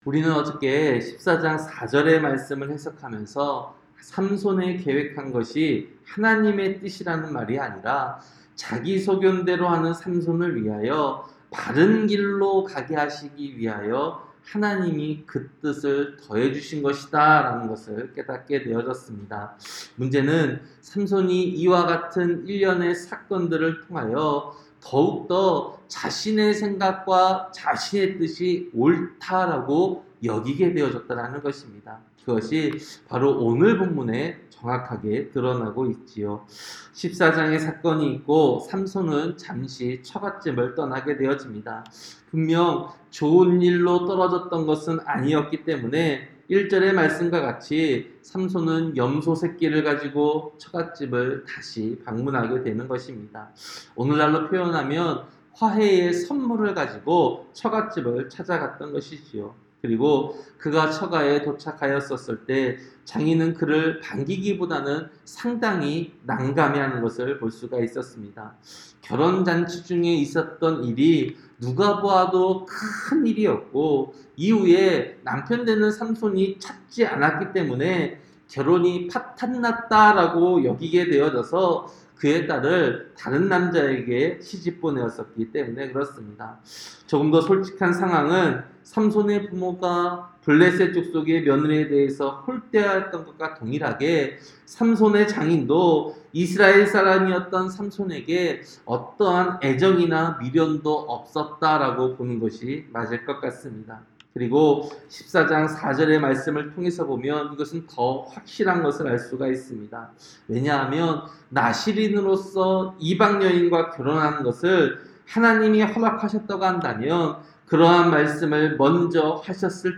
새벽설교-사사기 15장